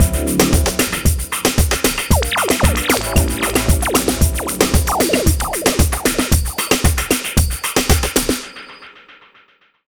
Ala Brzl 2 Full Mix 3b-D.wav